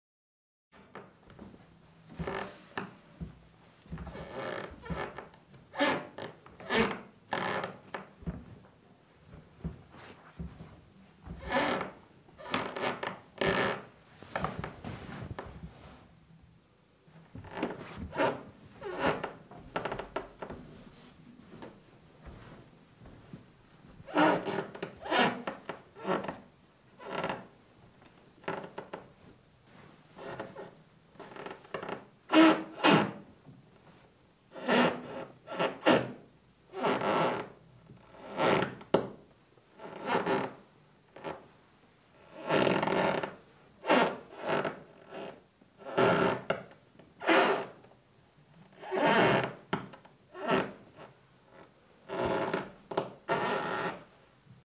Звуки скрипа дерева
На этой странице собрана коллекция натуральных звуков скрипящего дерева. Вы можете слушать онлайн или скачать в mp3 скрип старых половиц, веток в лесу, дверей и элементов деревянного дома.